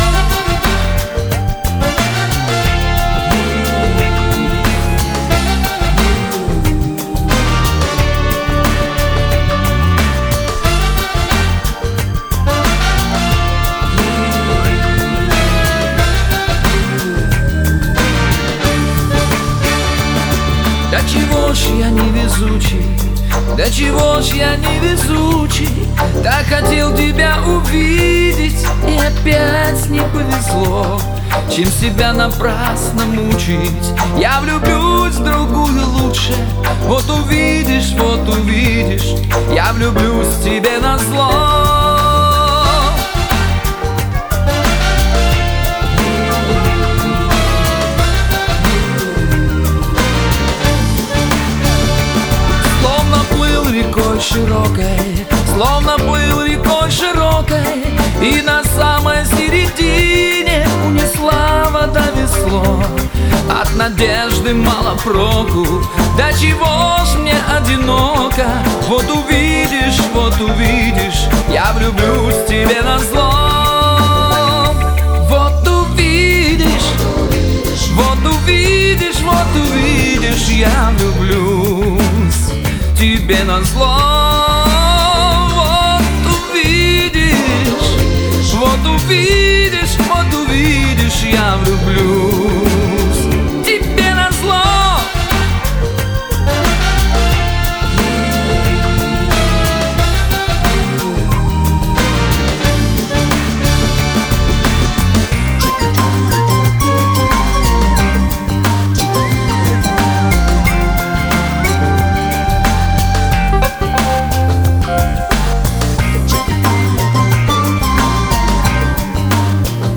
вокал, гитара
бэк вокал
альт-саксофон, клавишные
ударные, перкуссия